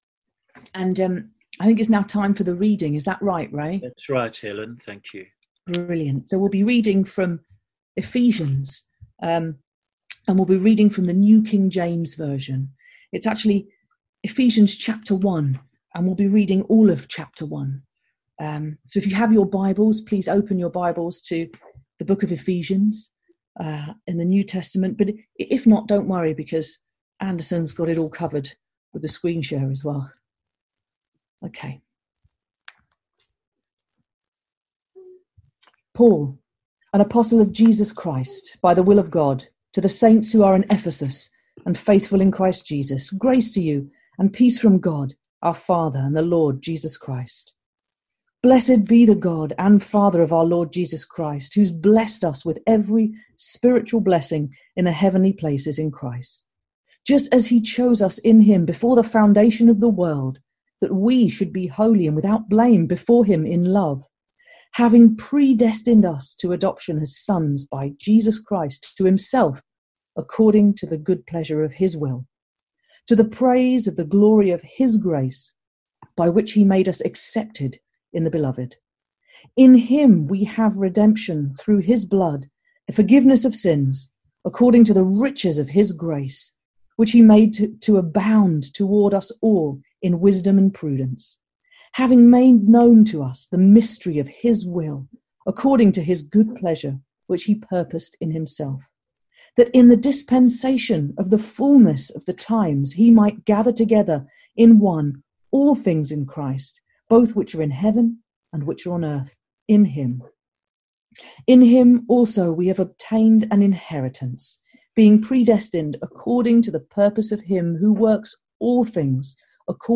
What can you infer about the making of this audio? Coronavirus the decision was taken to live-stream services online.